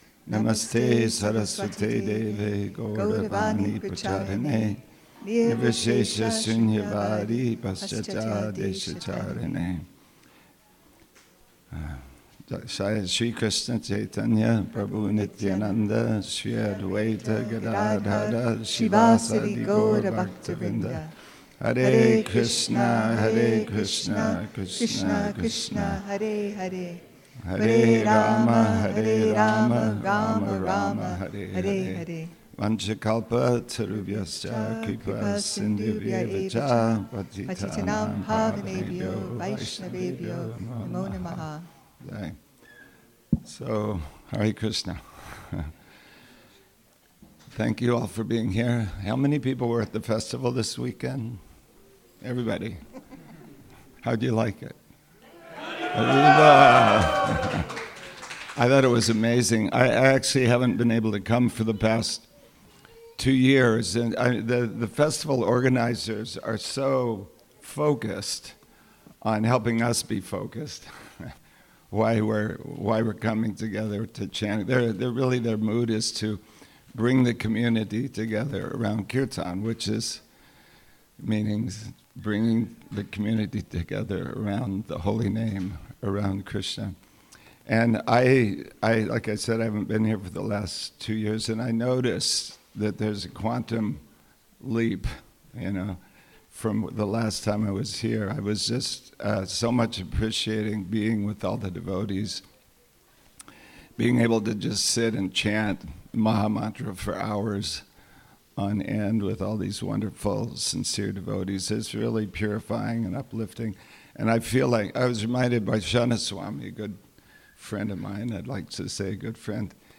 Sunday Feast Lecture, The Art of Kirtan
2024. at the Hare Krishna Temple in Alachua, Florida